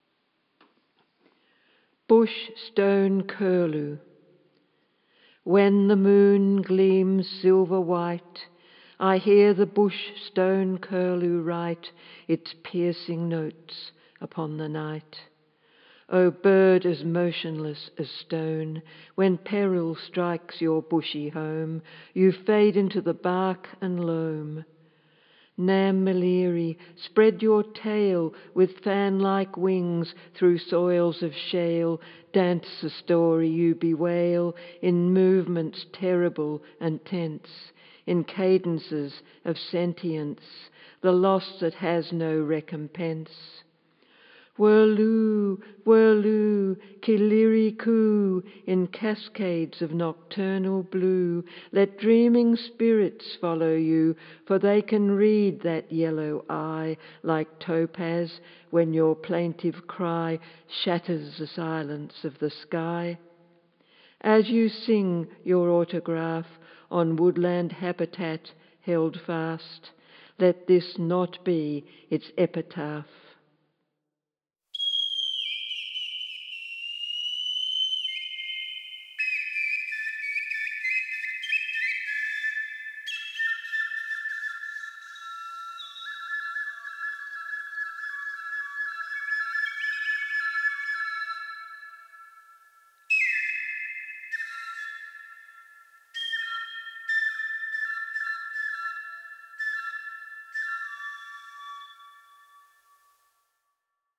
Eagle Feather Flute, Bamboo Horn and Bamboo Windpipe